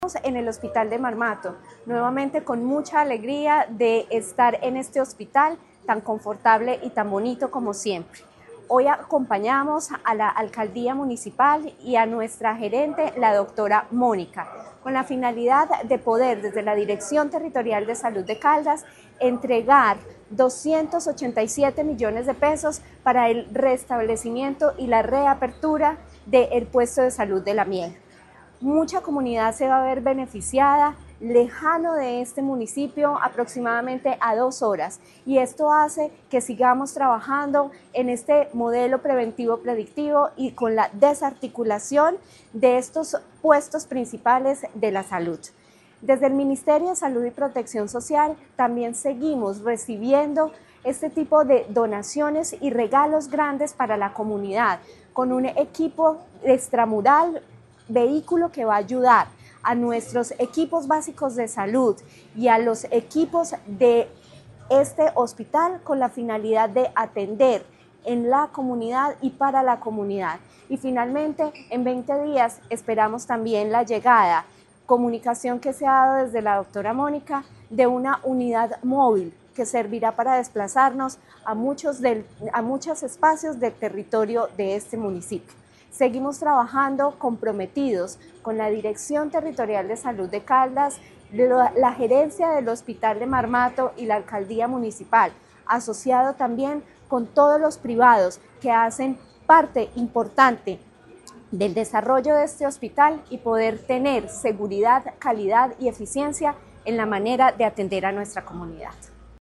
Natalia Castaño Díaz, directora de la DTSC.